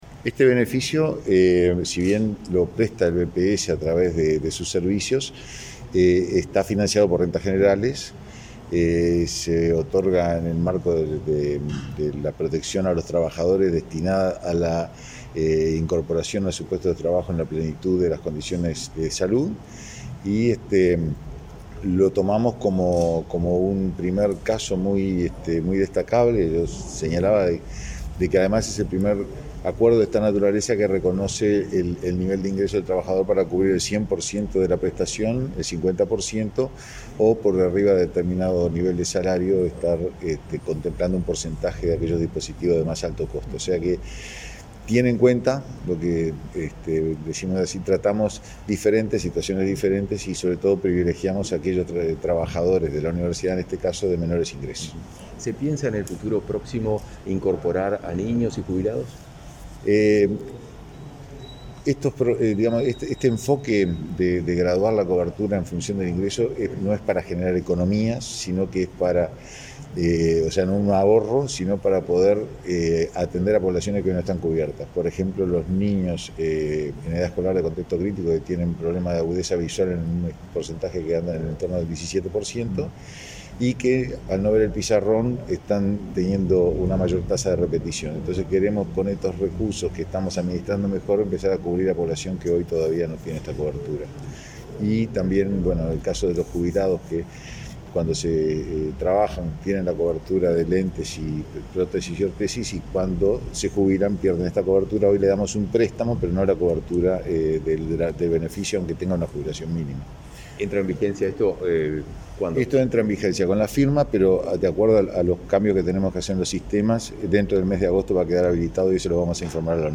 Declaraciones del presidente del Banco de Previsión Social, Hugo Odizzio